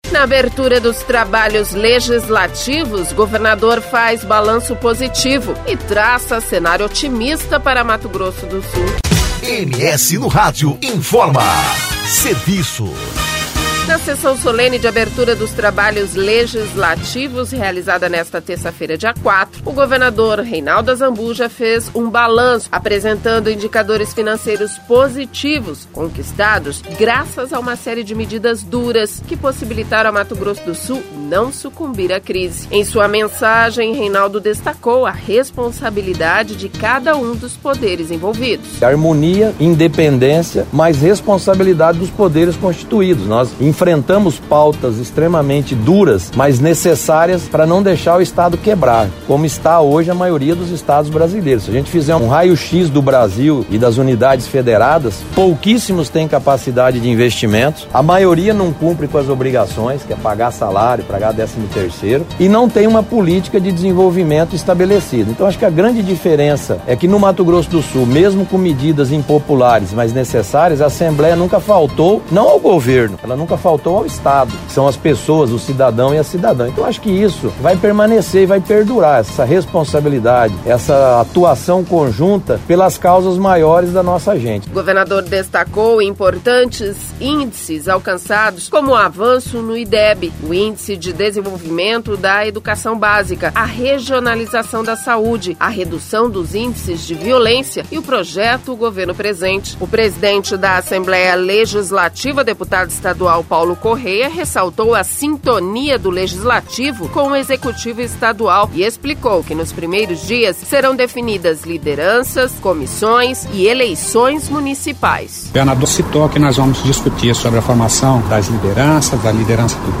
Campo Grande (MS) – Na sessão solene de abertura dos trabalhos legislativos, realizada nesta terça-feira, dia 04, o governador Reinaldo Azambuja fez um balanço apresentando indicadores financeiros positivos conquistados graças a uma série de medidas duras, que possibilitaram a Mato Grosso do Sul não sucumbir à crise.